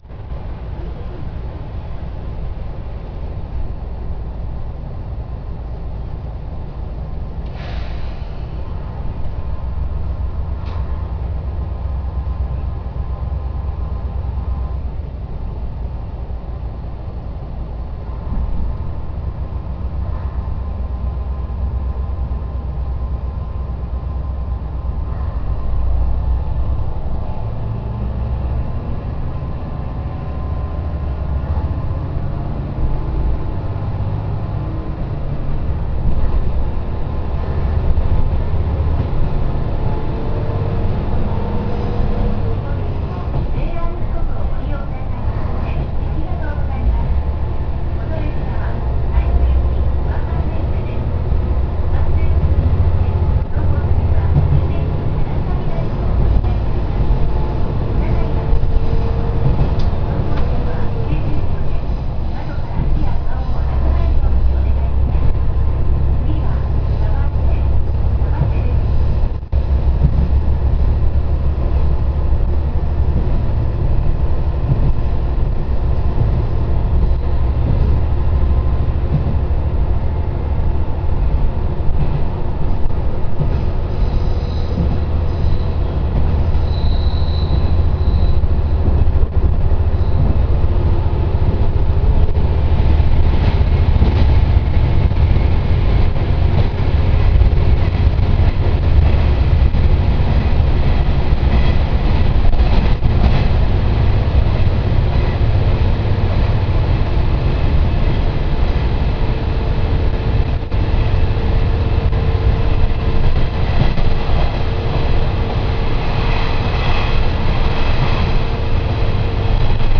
〜車両の音〜
・1500形走行音
【牟岐線】牟岐→鯖瀬（4分40秒：1.48MB）
毎度ながらのwikipedia引用ですが、コマツ製の直列6気筒ディーゼル機関 SA6D140HE-2 (450PS) を1基搭載しているそうです。従来と比べて環境にも優しいとのことですが、音は特別他の車両と変わらない気がします。